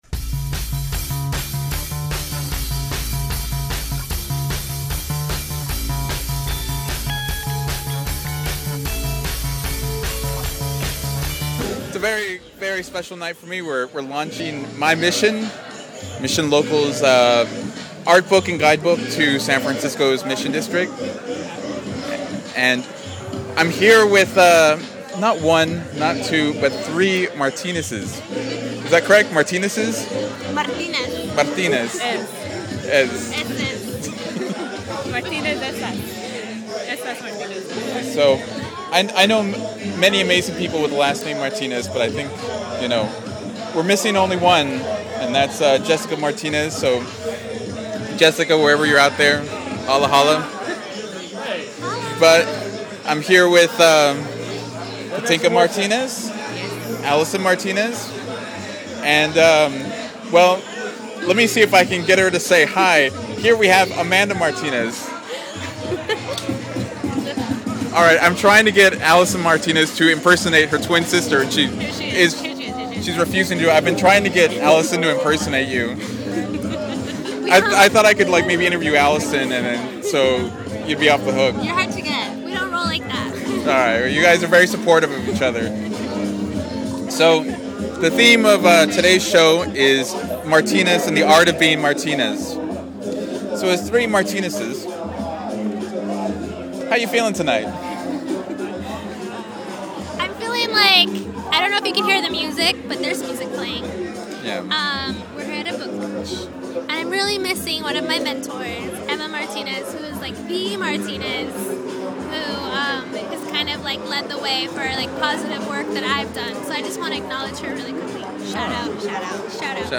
A dream came true for me this weekend with the launch of My Mission at Artillery Apparel and Gallery.